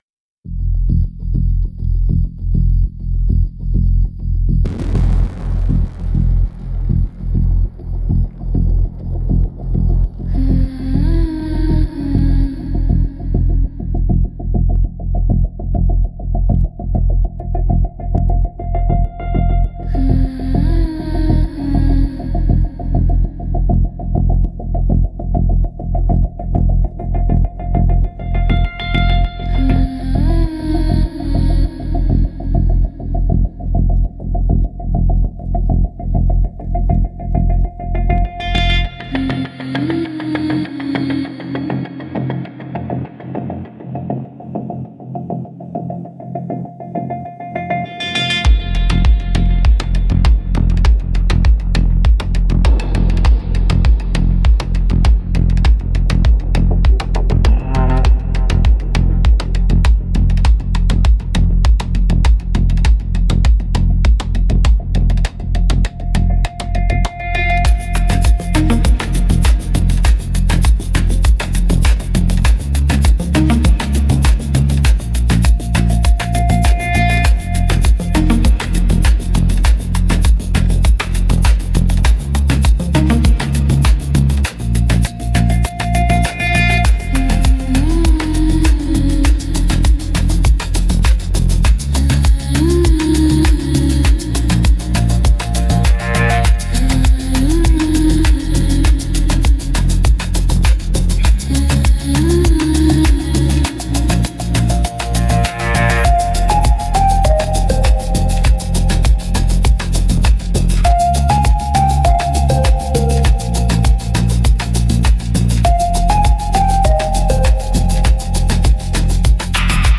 Instrumentals - Subterranean Frequency